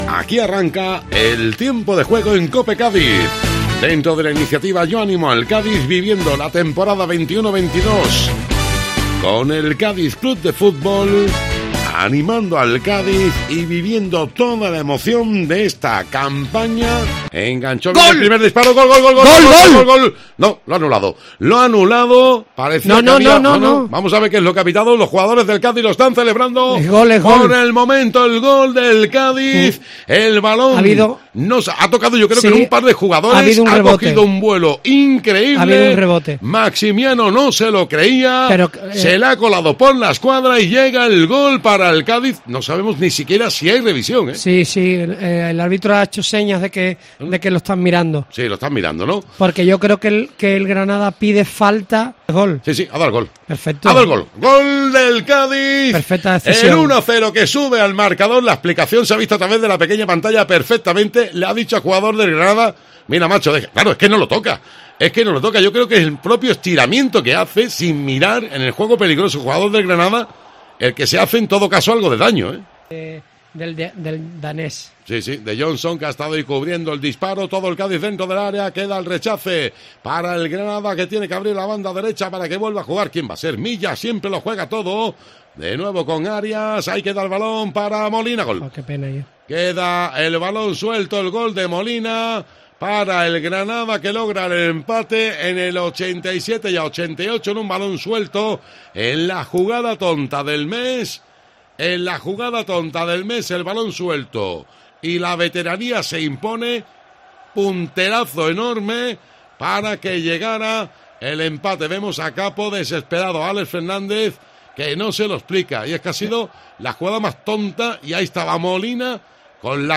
El resumen sonoro del Cádiz 1-1 Granada